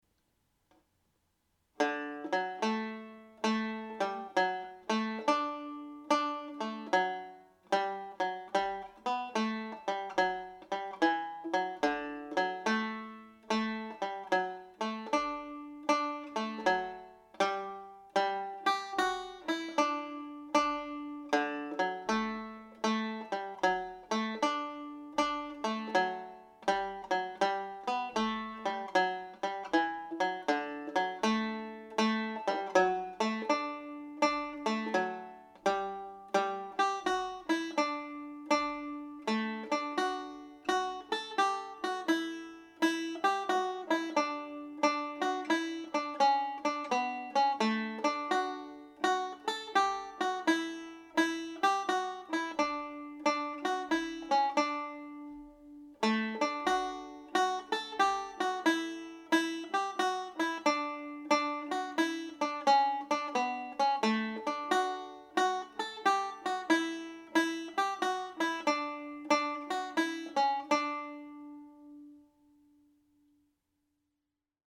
Sonny’s Mazurka played slowly